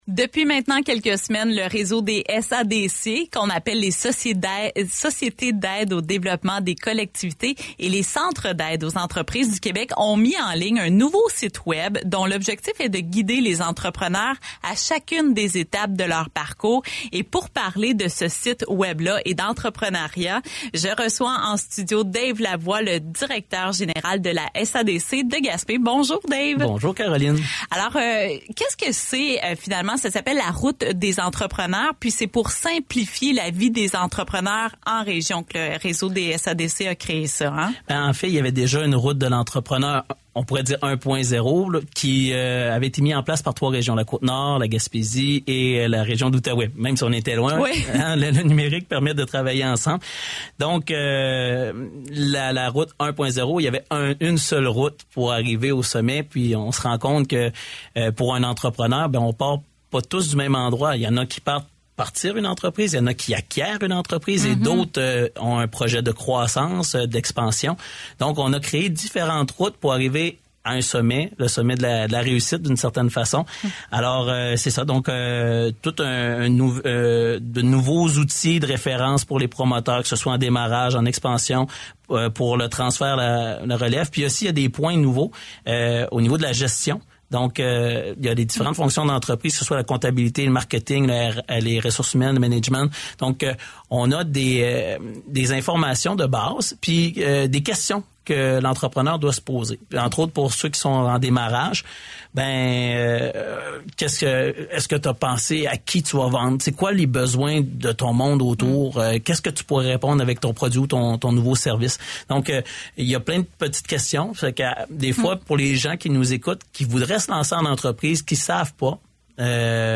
je reçois en studio